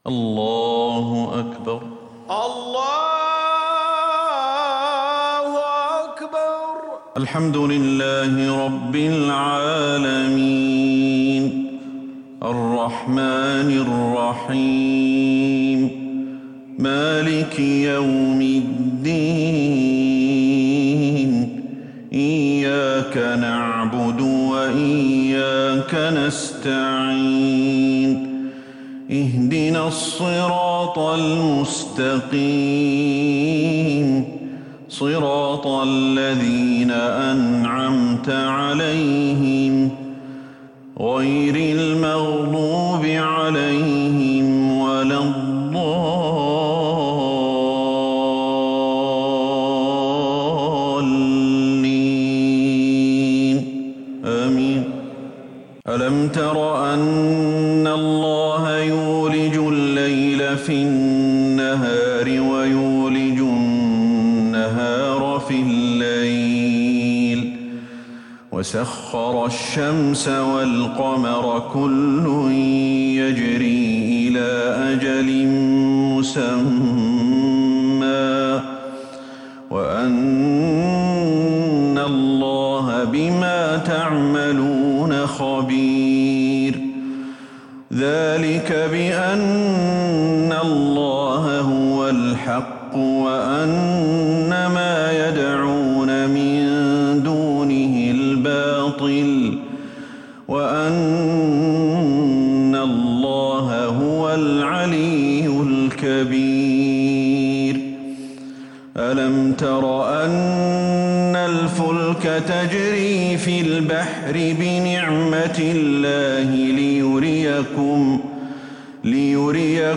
صلاة المغرب للقارئ أحمد الحذيفي 11 جمادي الأول 1442 هـ
تِلَاوَات الْحَرَمَيْن .